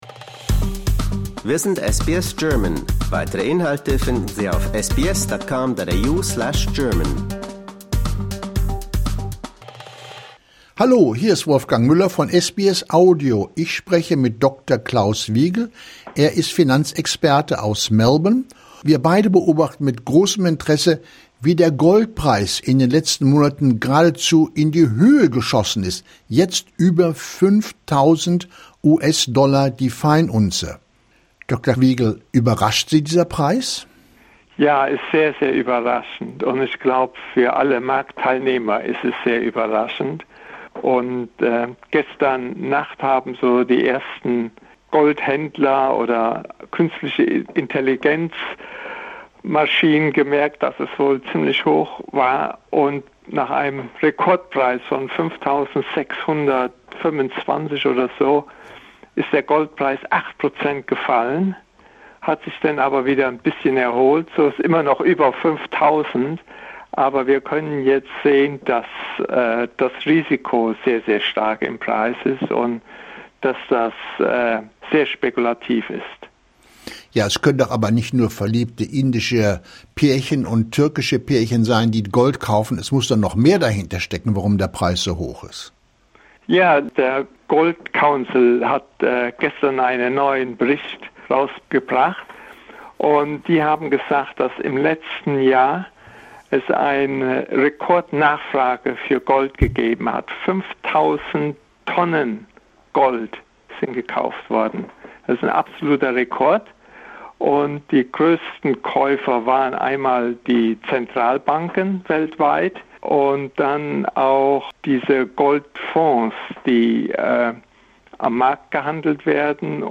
Disclaimer: Wir möchten Sie darauf hinweisen, dass die in diesem Beitrag geäußerten Meinungen die persönlichen Ansichten der interviewten Person/des Gesprächspartners darstellen.